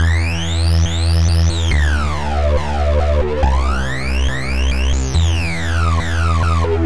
Tag: 14 bpm Dubstep Loops Synth Loops 1.16 MB wav Key : Unknown